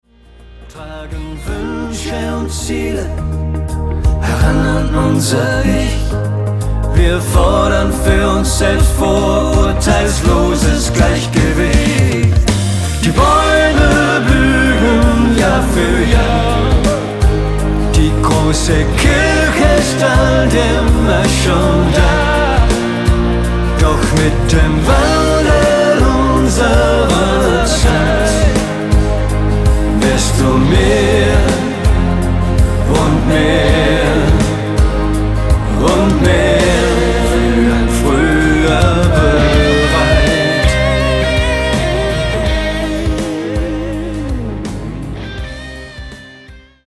eine hörbar harmonische Zusammenarbeit.